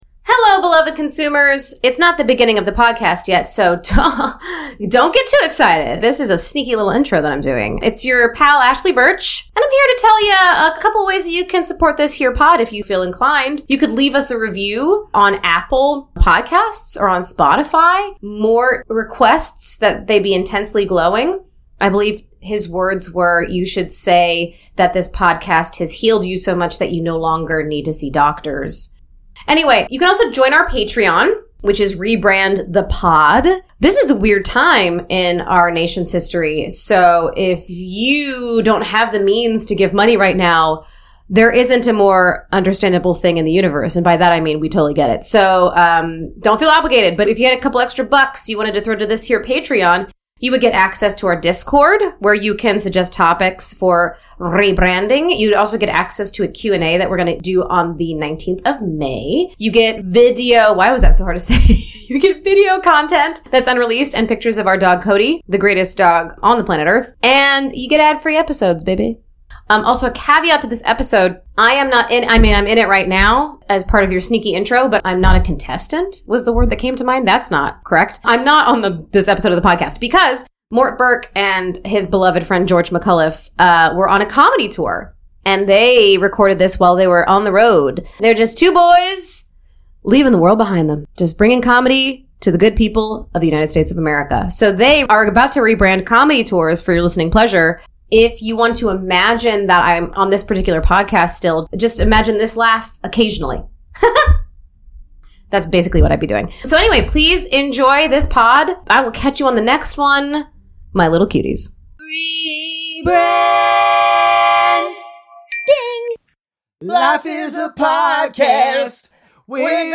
The boys are recording in Salt Lake City while finishing up a midwest tour.